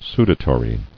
[su·da·to·ry]